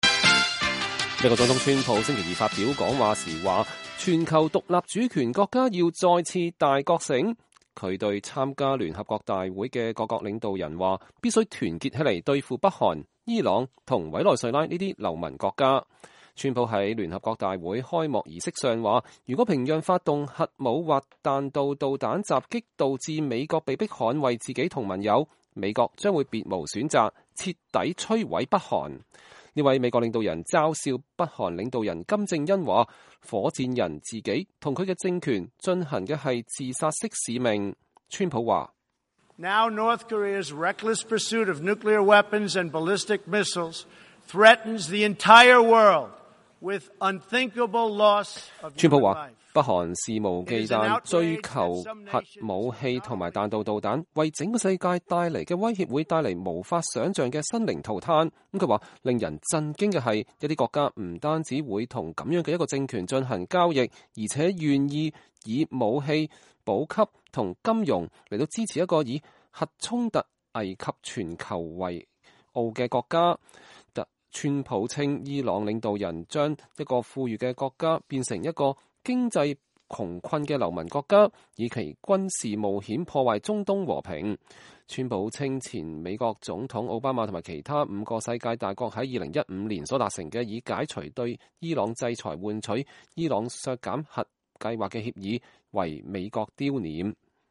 美國總統川普星期二在聯合國大會發表講話